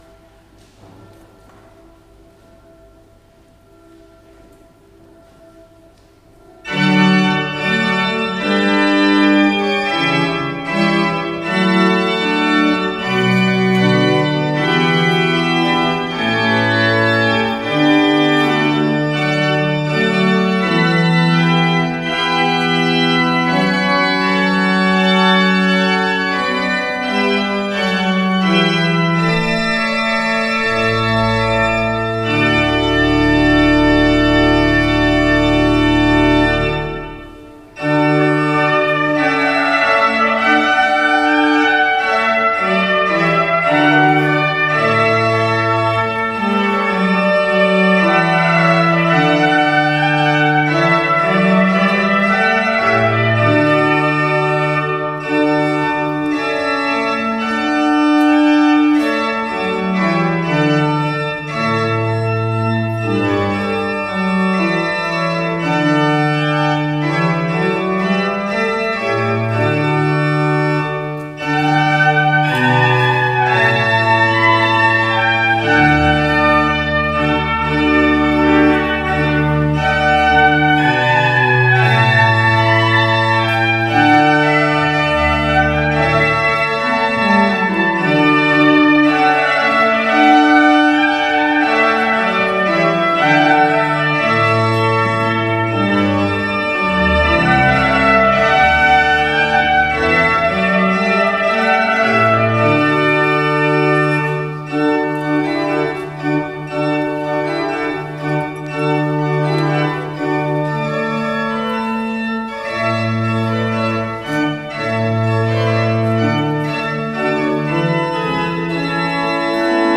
Trški vrh, 15.09.2023 U hodočasničkoj crkvi Majke Božje Jeruzalemske održano je noćno molitveno bdijenje (vigil) i euharistijsko klanjanje.